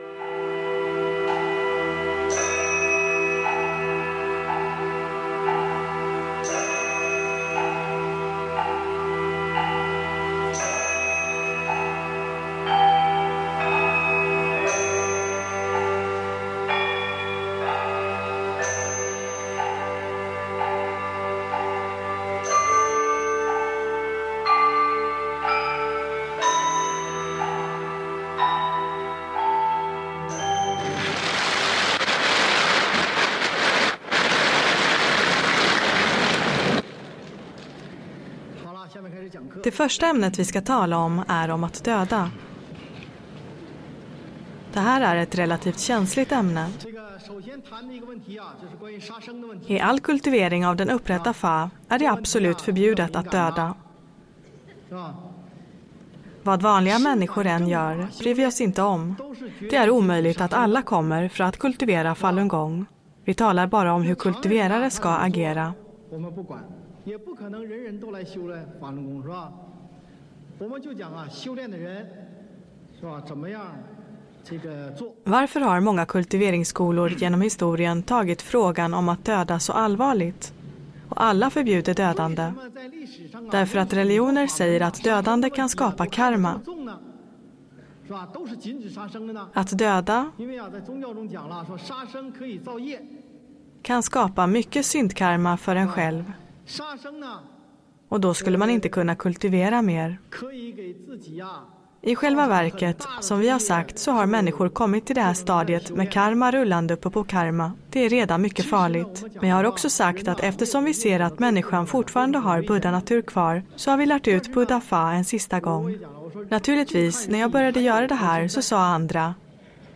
Föreläsning 1